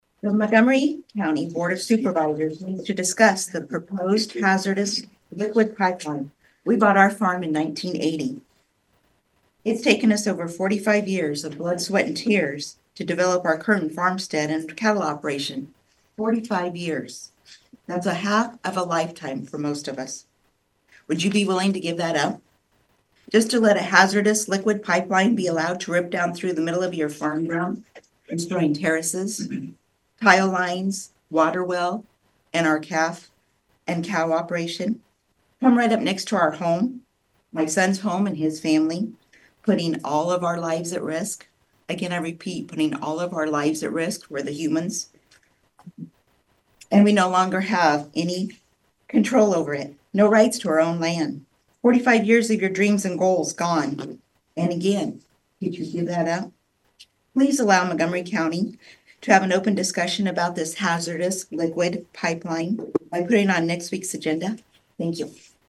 During the public comment period, the speakers consisted of most property owners affected by the pipeline’s proposed route.
A property owner in West Township also urged the supervisors to place the pipeline discussion on next week’s agenda.